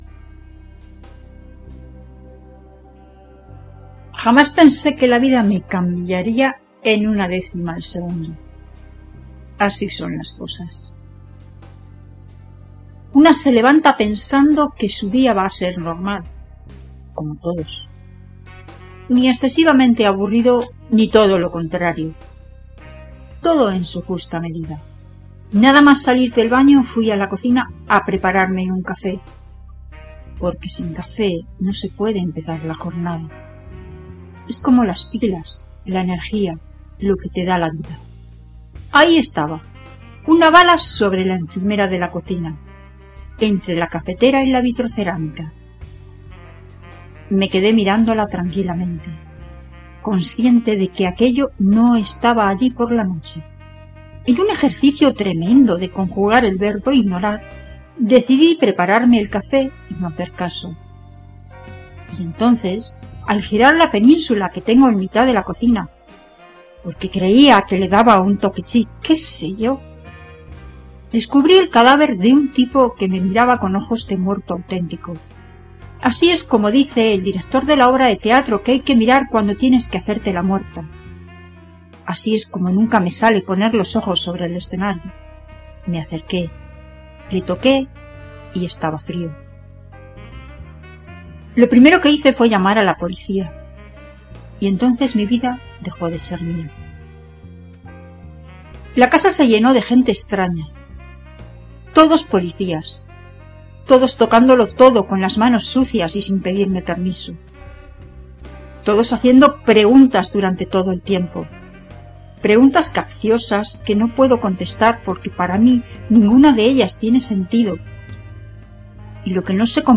Puedes clicar en la ilustración y escuchar el relato con mi voz